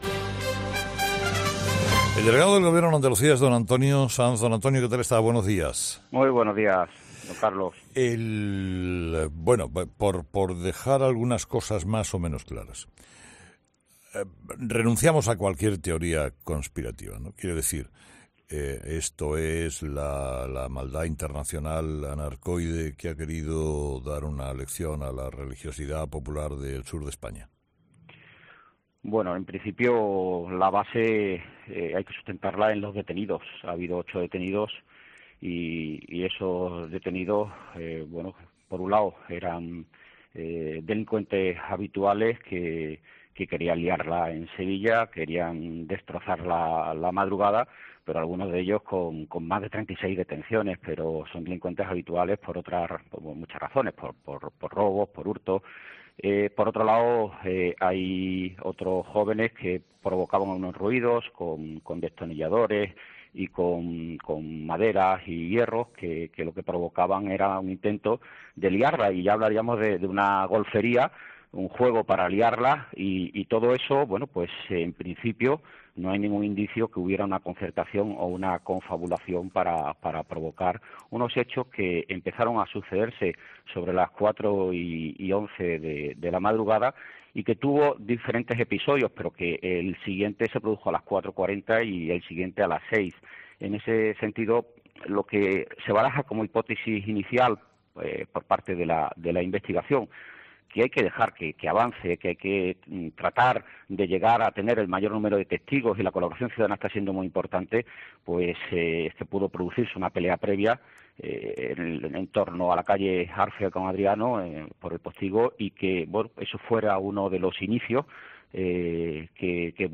Escucha la entrevista al delegado del Gobierno en Andalucía, Antonio Sanz, en 'Herrera en COPE'